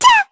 Lavril-Vox_Attack1_kr.wav